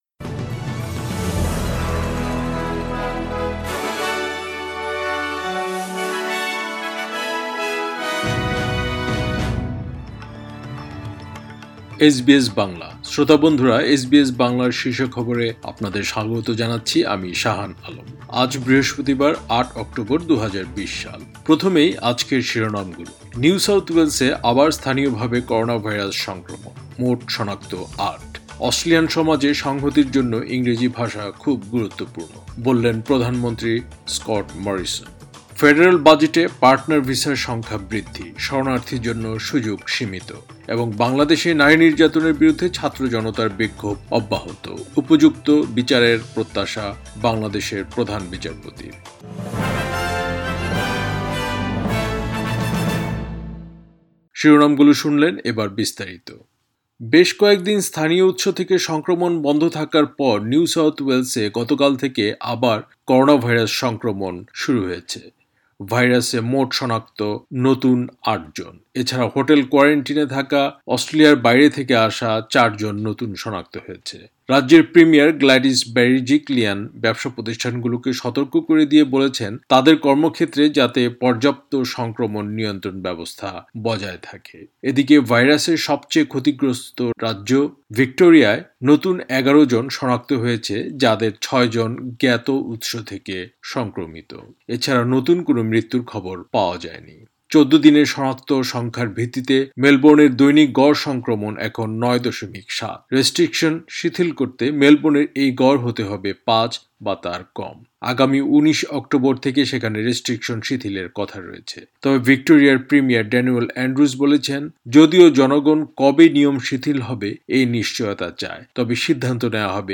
এসবিএস বাংলা শীর্ষ খবর, ৮ অক্টোবর, ২০২০